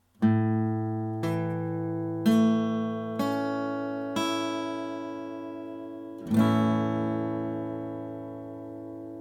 Der A-Dur-Akkord wird aus dem 1. (Grundton), 3. und 5. Ton der A-Dur-Tonleiter aufgebaut, die in diesem Fall sind: A, Cis und E. Diese drei Töne werden auch als Dreiklang bezeichnet.
A-Dur (Offen)
A-Dur.mp3